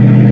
engine6.ogg